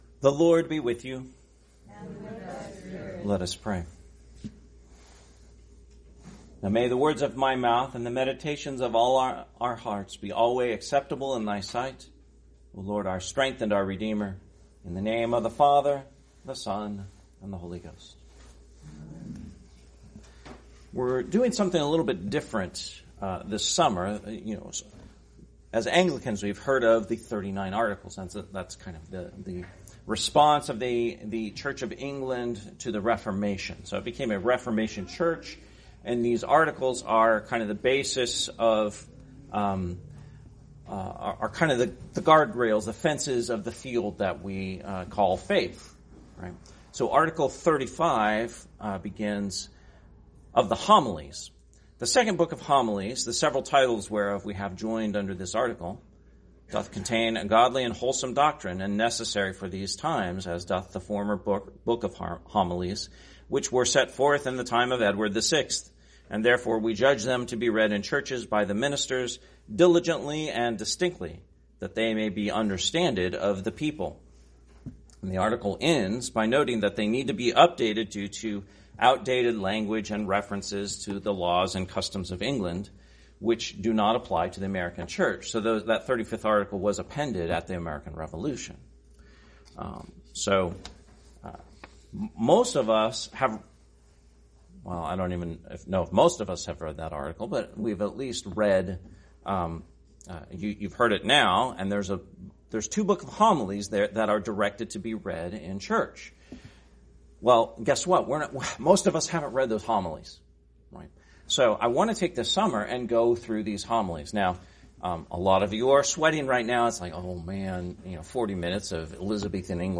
Sermon, First Sunday after Trinity, 2025 – Christ the King Anglican Church